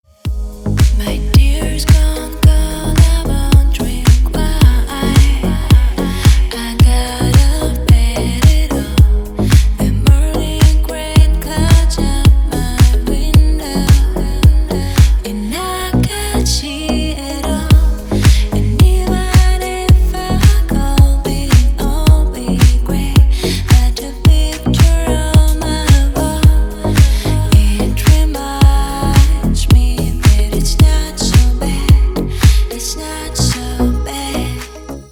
🎶 Рингтоны / Красивые мелодии и рингтоны